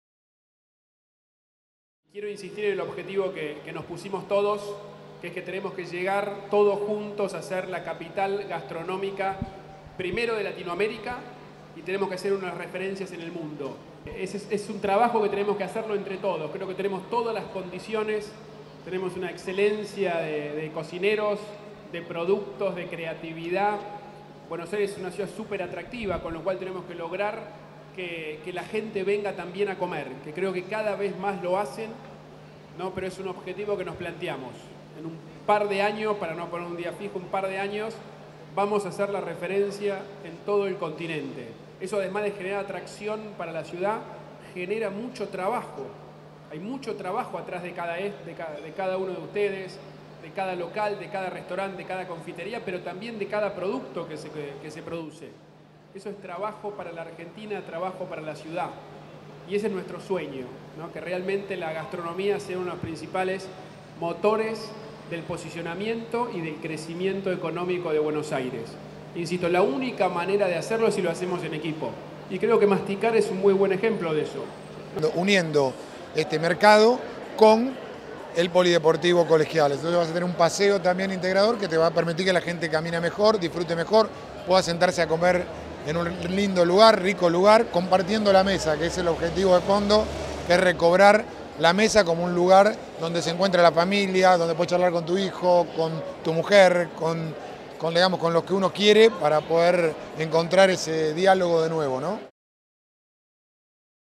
El jefe de Gobierno de la Ciudad de Buenos Aires, Horacio Rodríguez Larreta, junto al viceje, Diego Santilli, presentó la edición 2016 de la Feria Masticar, que bajo el lema “Comer rico hace bien”, se desarrollará entre el 5 y el 8 de mayo próximos.